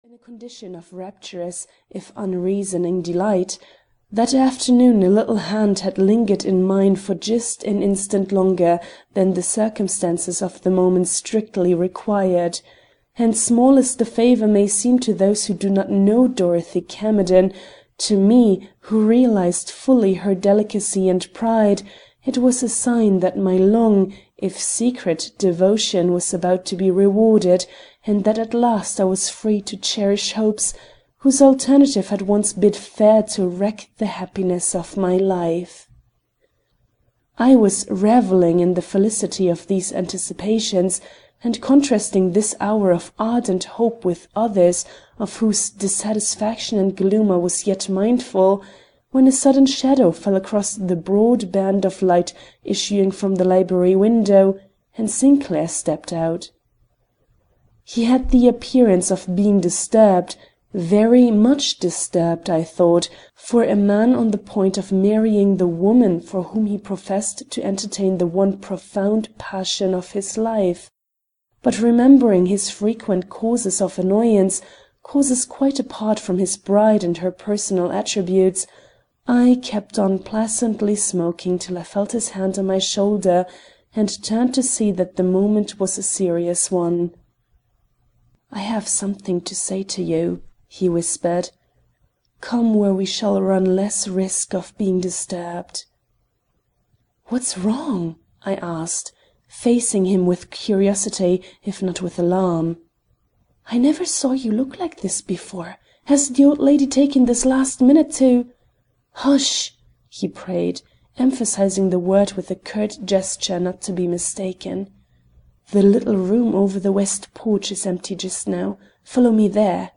The Amethyst Box (EN) audiokniha
Ukázka z knihy